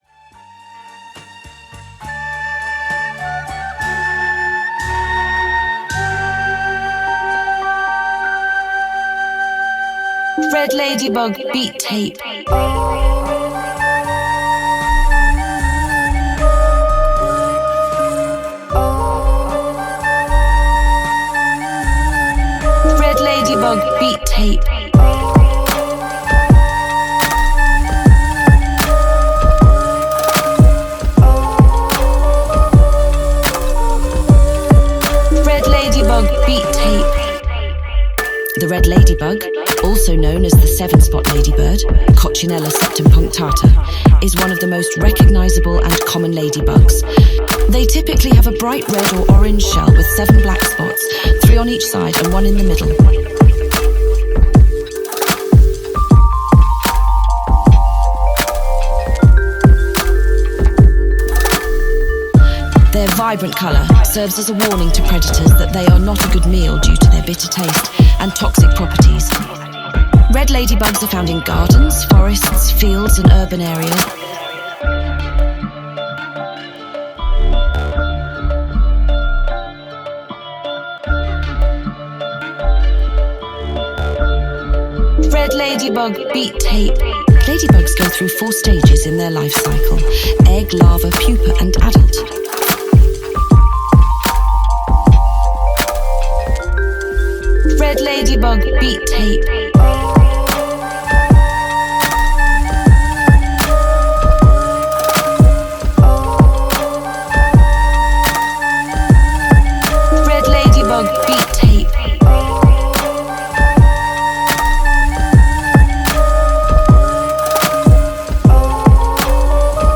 2. Boom Bap Instrumentals